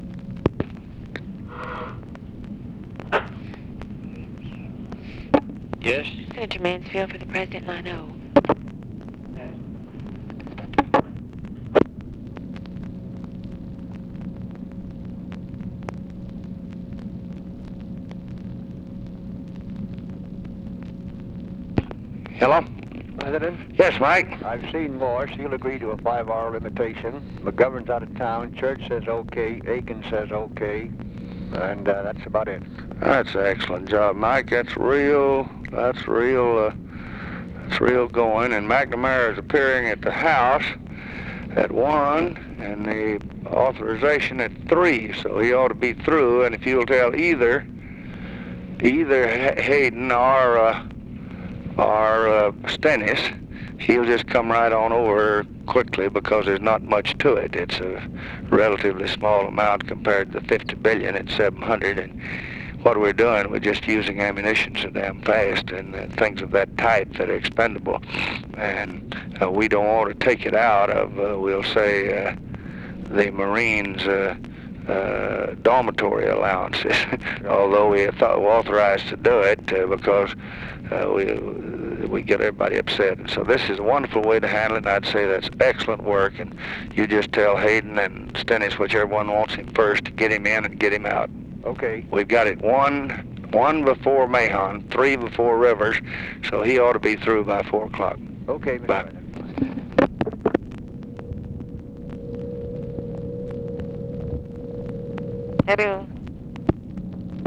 Conversation with MIKE MANSFIELD, May 4, 1965
Secret White House Tapes